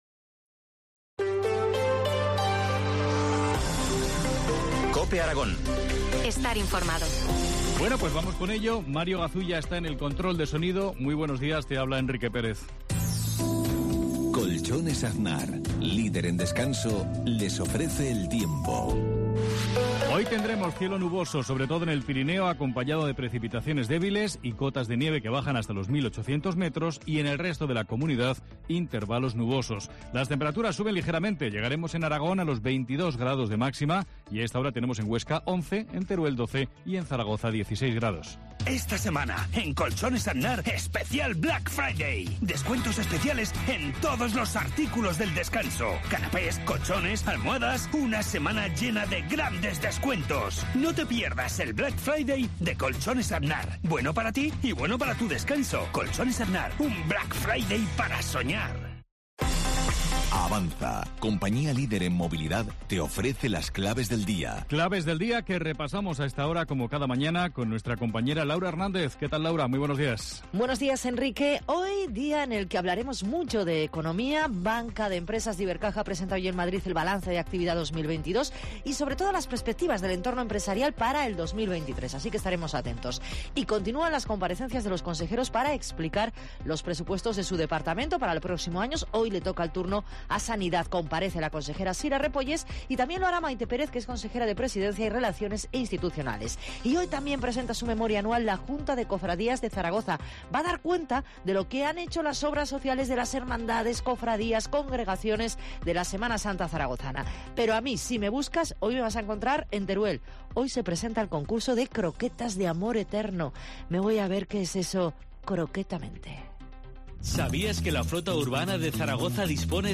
Informativo local Herrera en COPE Aragón-Huesca 07.50h